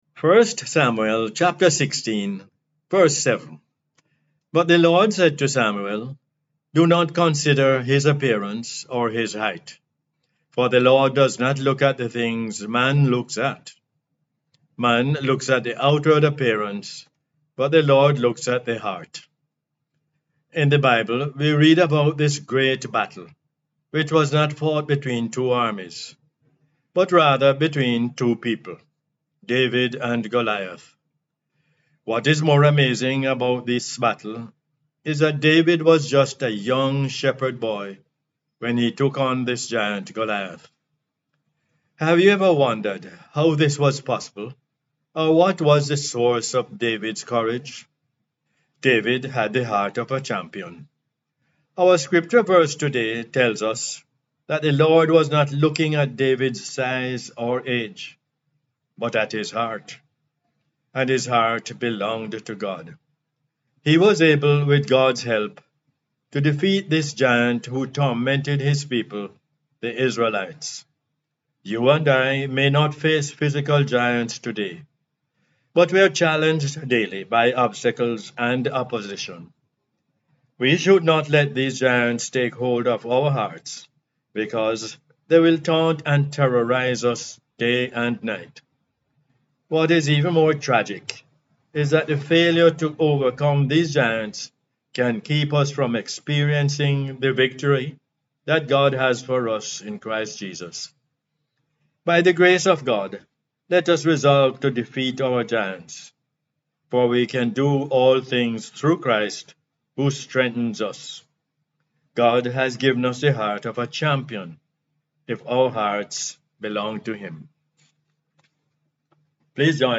1 Samuels 16:7 is the "Word For Jamaica" as aired on the radio on 3 February 2023.